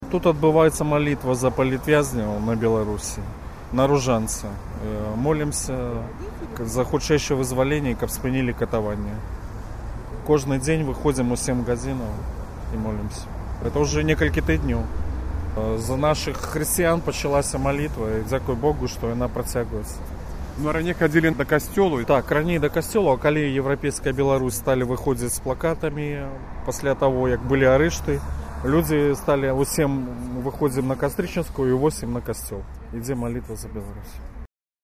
адзін з удзельнікаў малітвы